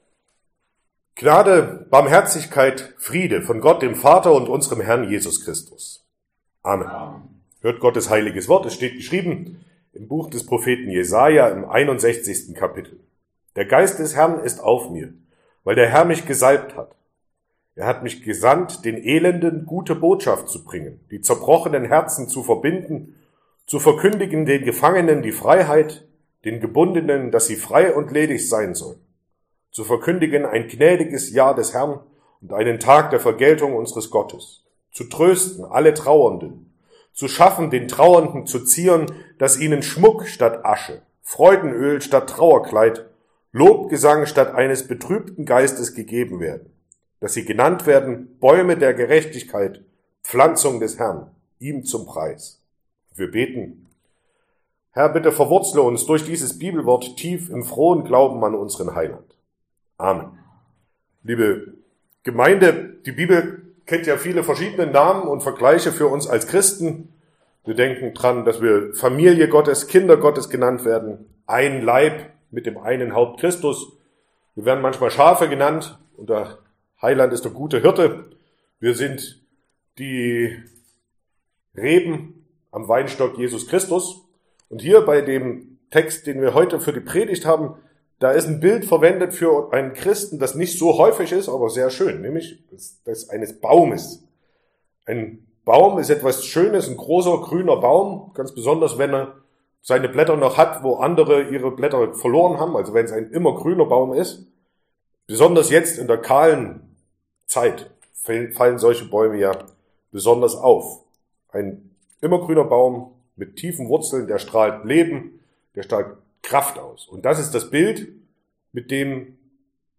Sonntag nach Epiphanias Passage: Jesaja 61, 1-3 Verkündigungsart: Predigt « Epiphanias 2023 3.